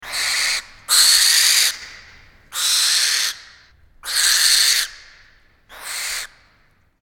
Barn Owl
long, hissing shriek.
barn.mp3